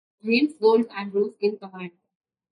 Pronounced as (IPA) /ɡəʊld/